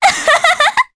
Artemia-Vox_Happy3_jp.wav